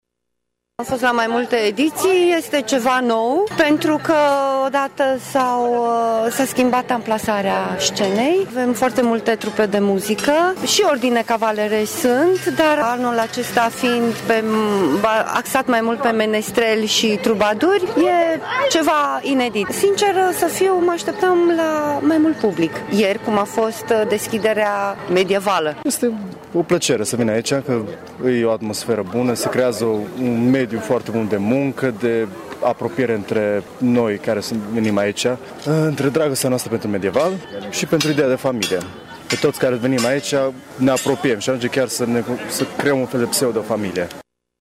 Domniţele şi cavalerii sunt pregătiţi să încânte turiştii chiar dacă sunt puţin dezamăgiţi de publicul mai putin numeros: